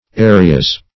Area \A"re*a\ ([=a]"r[-e]*[.a]; 277), n.; pl. Areas (-[.a]z) .